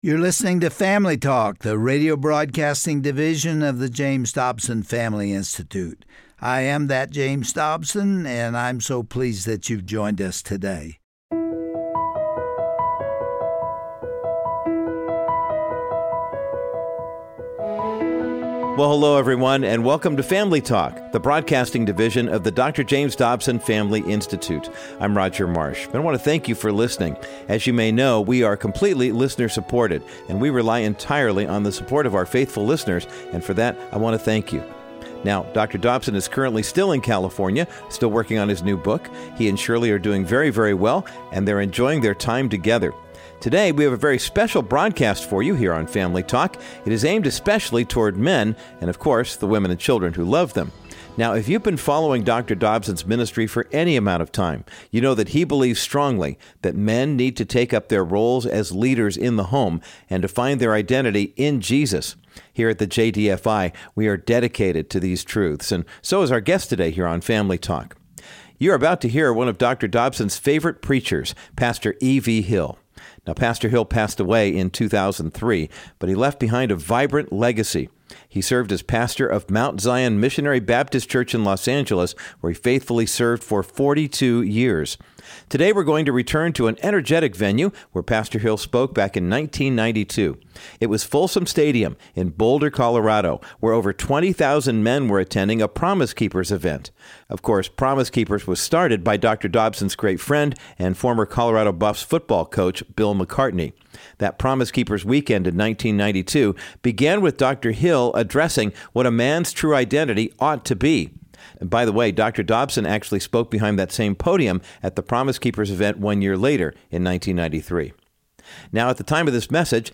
preached a powerful sermon at a 1992 Promise Keepers event in Boulder